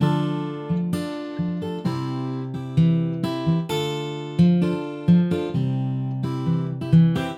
吉他主题
标签： 88 bpm Hip Hop Loops Guitar Acoustic Loops 3.67 MB wav Key : F Cubase
声道立体声